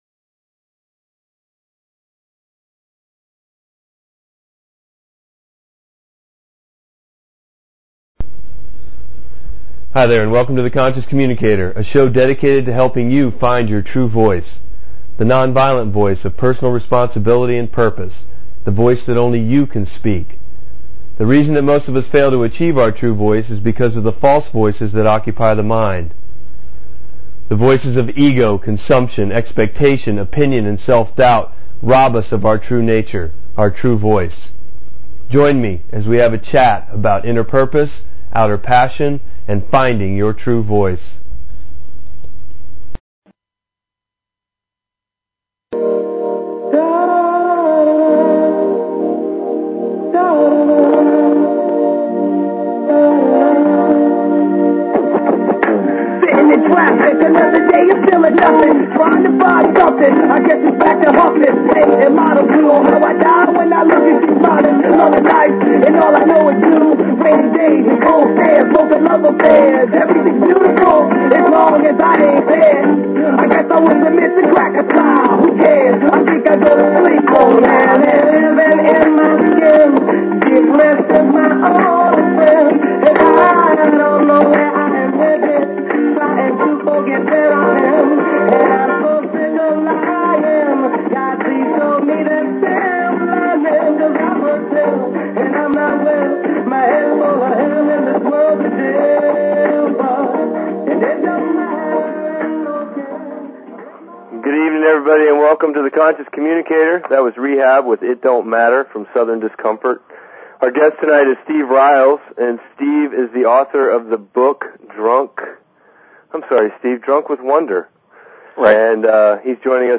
Talk Show Episode, Audio Podcast, The_Conscious_Communicator and Courtesy of BBS Radio on , show guests , about , categorized as